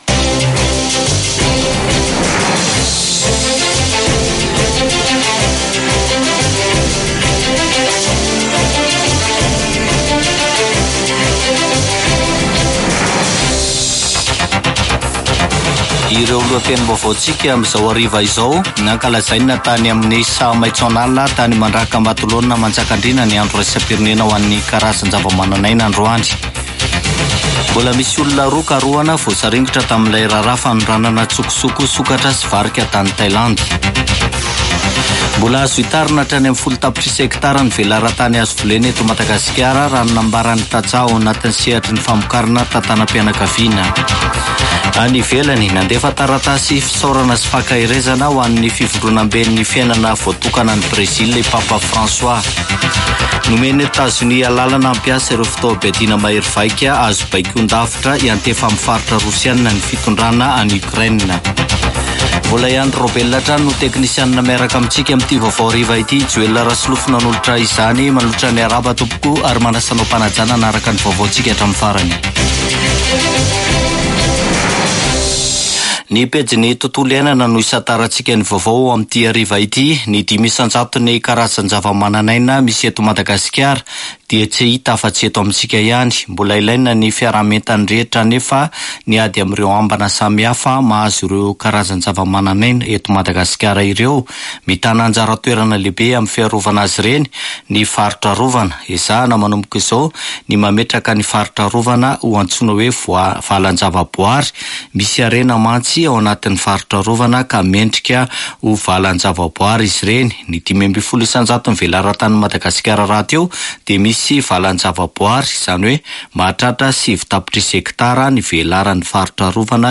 [Vaovao hariva] Zoma 31 mey 2024